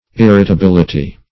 Irritability \Ir`ri*ta*bil"i*ty\, n. [L. irritabilitas: cf. F.